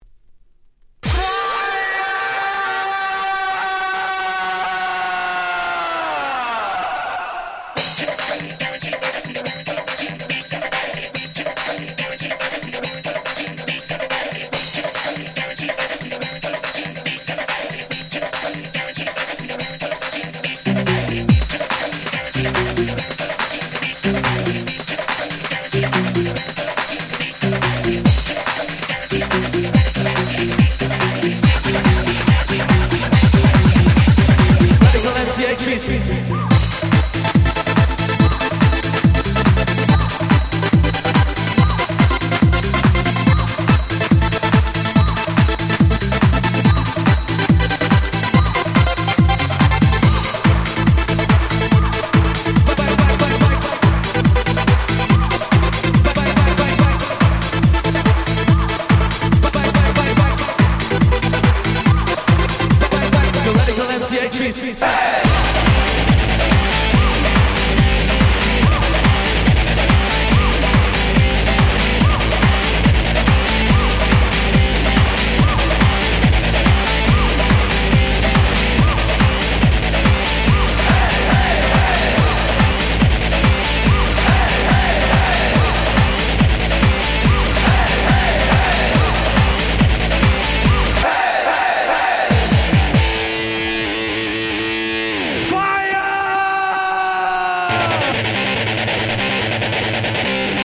97' Dance Pop / Trance Super Hit !!
90's トランス